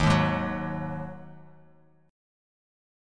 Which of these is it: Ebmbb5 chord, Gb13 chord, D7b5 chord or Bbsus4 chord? Ebmbb5 chord